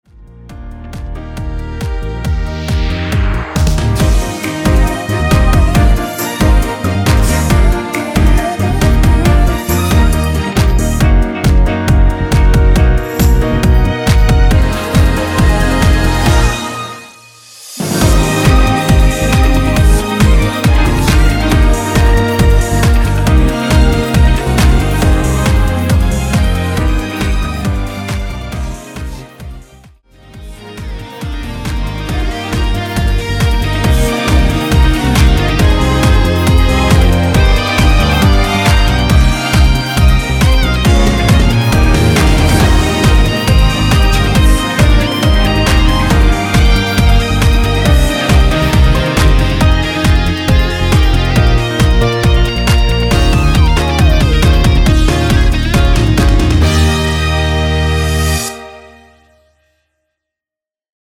원키에서 (-1)내린 코러스 포함된 MR 입니다.
엔딩이 페이드 아웃이라 노래 하시기 좋게 엔딩을 만들어 놓았습니다.(미리듣기 참조)
Eb
앞부분30초, 뒷부분30초씩 편집해서 올려 드리고 있습니다.